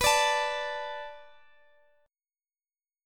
Listen to Bm7 strummed